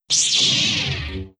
Activate Lightsaber Sound.wav